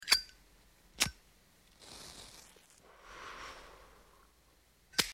Звуки марихуаны
Зиппо зажигалкой подожгли косяк с травой